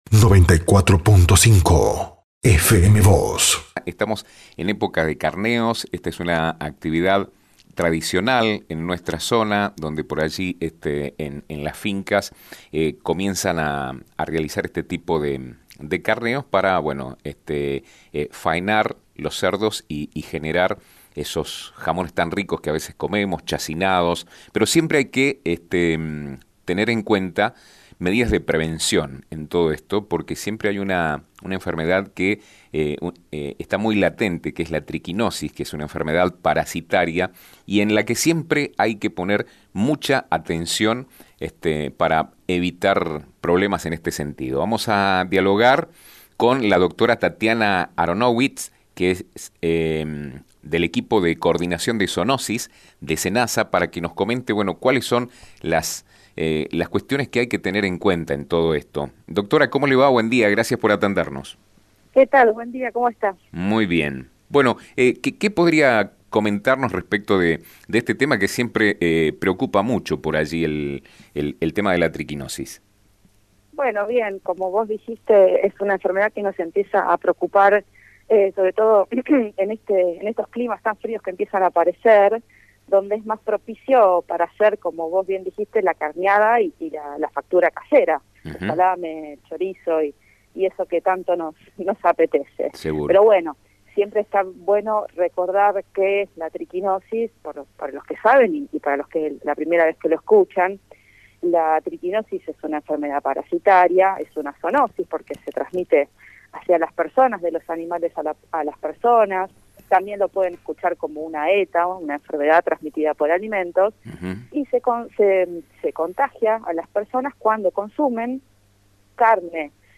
No obstante, deben tenerse en cuenta una serie de medidas de prevención, especialmente contra la triquinosis, enfermedad parasitaria de gran peligrosidad. Sobre este tema habló con FM os (94.5) y Diario San Rafael